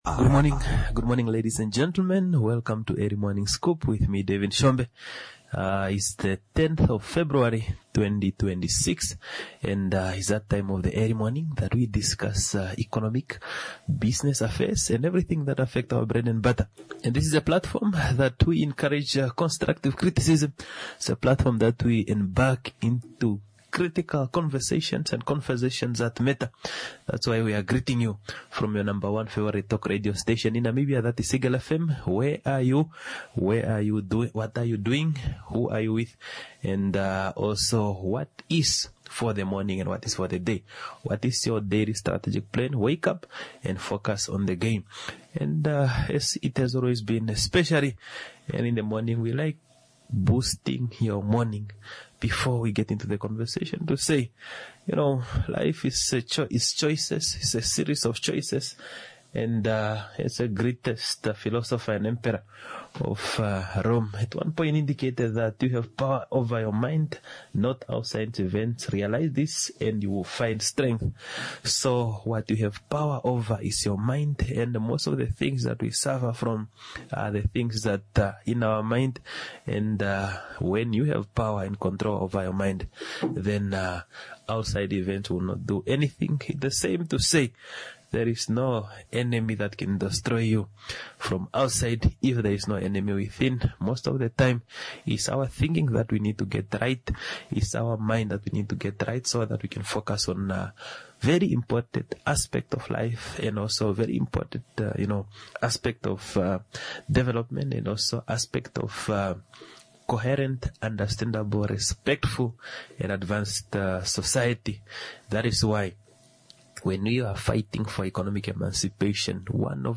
In conversation with Martin Lukato Lukato on parliamentary duties and Petroleum Amendment Bill"